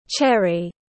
Màu đỏ anh đào tiếng anh gọi là cherry, phiên âm tiếng anh đọc là /’t∫eri/.
Cherry /’t∫eri/